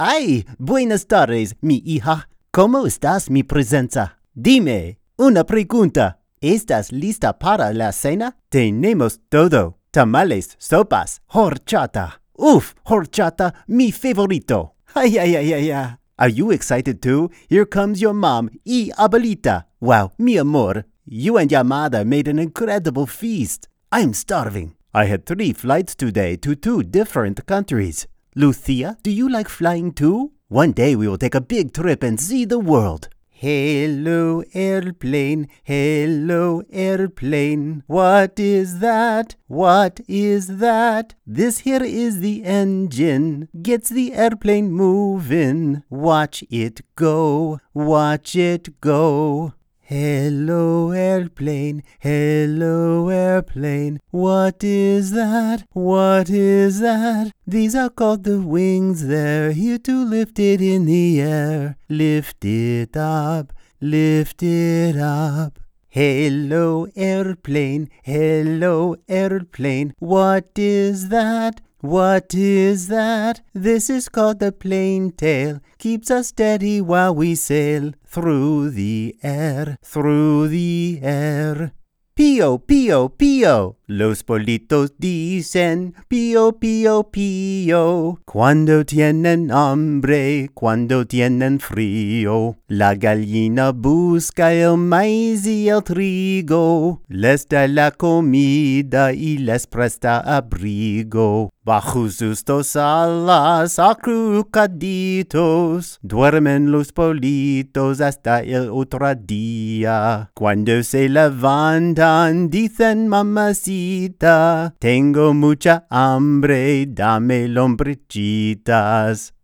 Demo
Young Adult, Adult
standard us | natural
ANIMATION 🎬